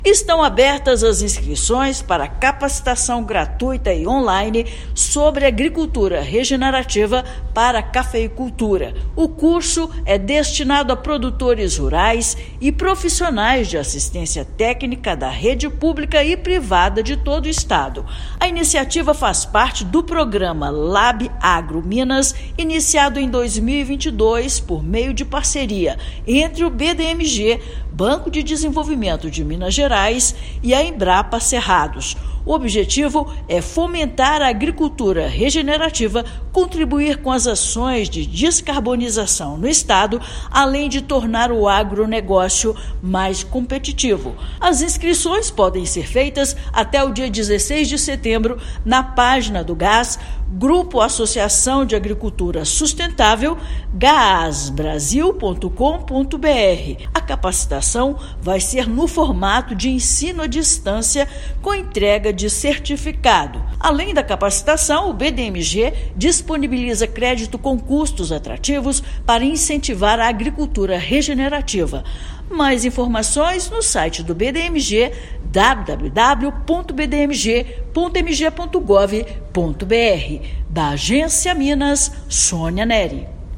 Destinada a produtores rurais e profissionais de assistência técnica, o curso online realizado em parceria com a Embrapa começa em 17/9. Ouça matéria de rádio.